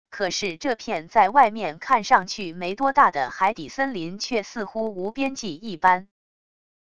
可是这片在外面看上去没多大的海底森林却似乎无边际一般wav音频生成系统WAV Audio Player